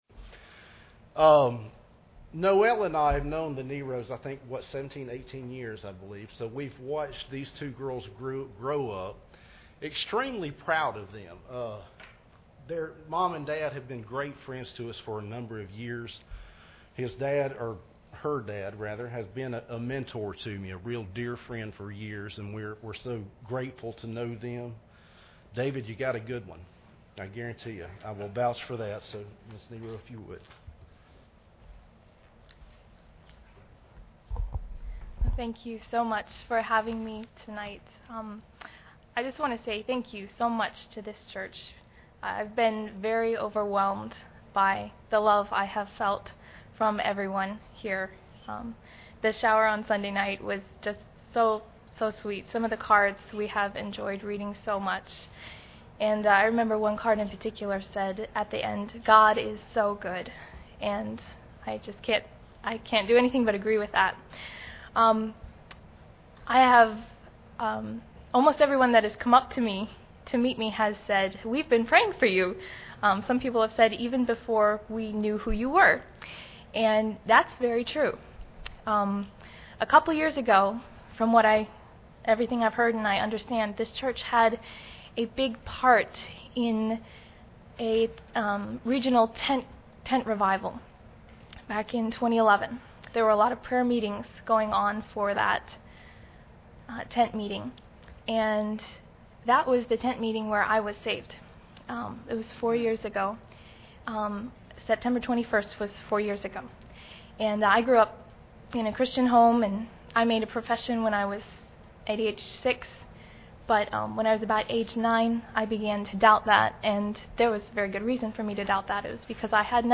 2015 Missions Conference Service Type: Special Service Preacher